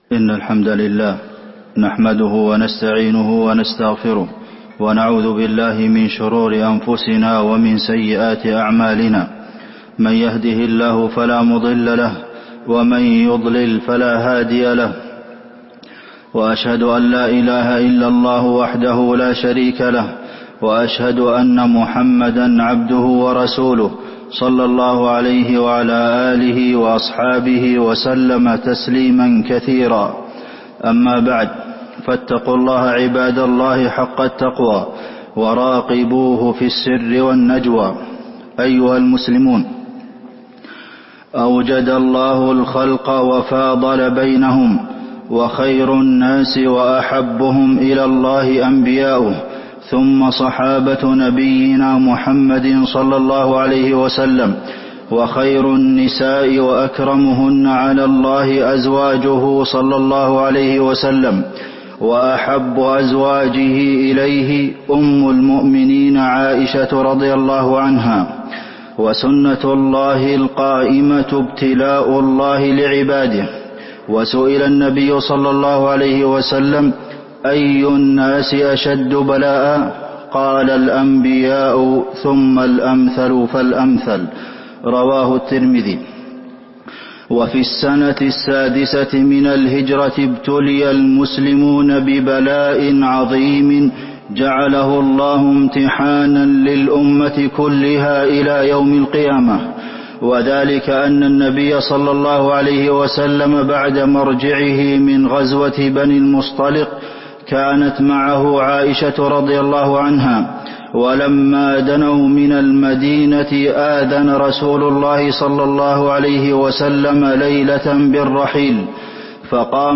تاريخ النشر ٢١ رجب ١٤٤٥ هـ المكان: المسجد النبوي الشيخ: فضيلة الشيخ د. عبدالمحسن بن محمد القاسم فضيلة الشيخ د. عبدالمحسن بن محمد القاسم حادثة الإفك عبر ومواعظ